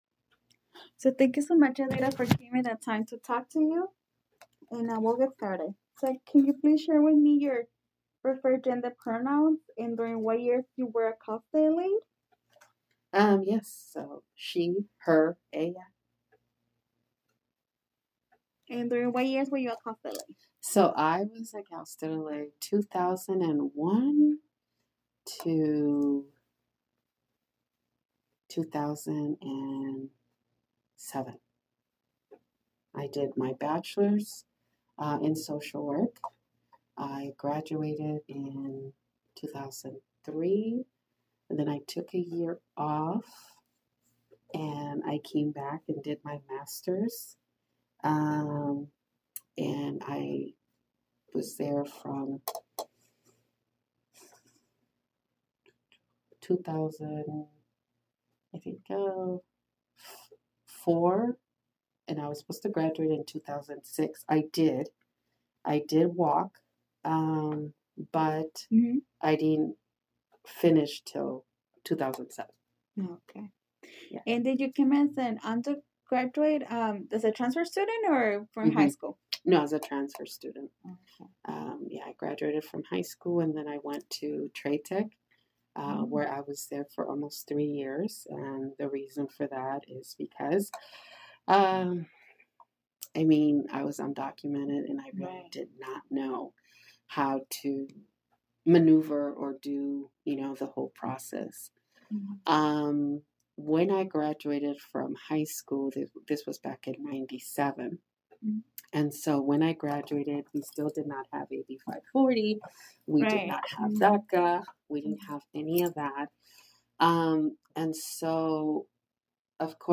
This is the first third of the interview.